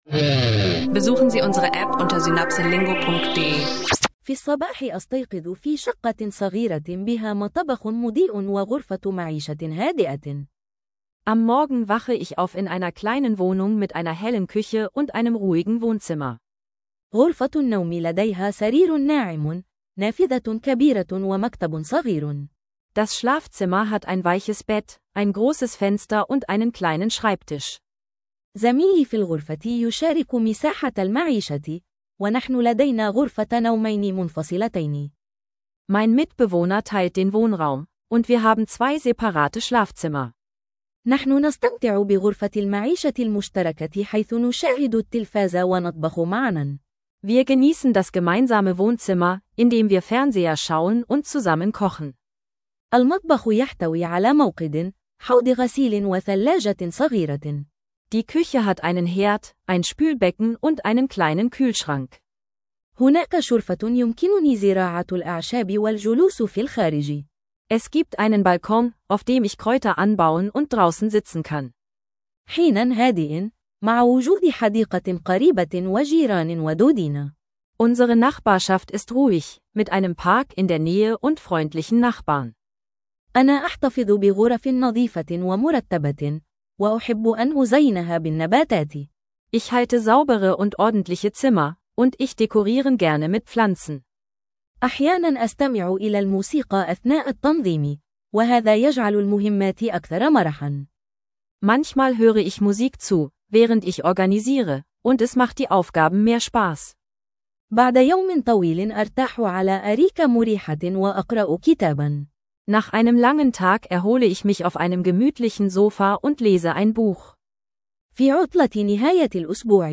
Ein praktischer Arabisch-Sprachkurs für Anfänger mit Alltagsvokabeln über Wohnbereiche, Viertel und ein inspirierendes Horoskop für den 25. November.